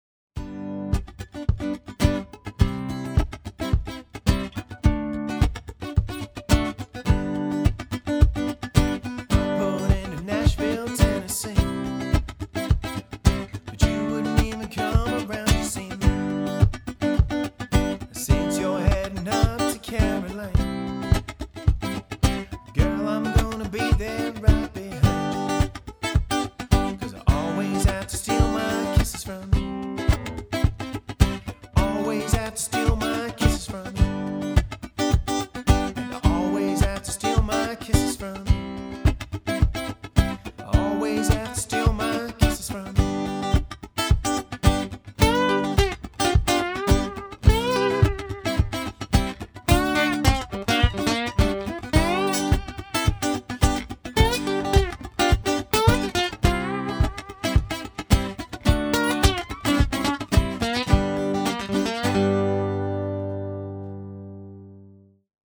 Solo acoustic musician hire